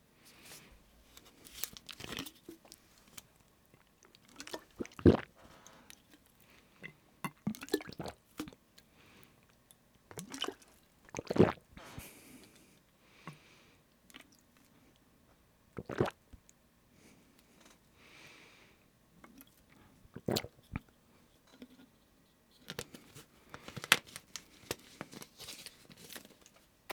Drinking From Water Bottle Sound Effect Free Download
Drinking From Water Bottle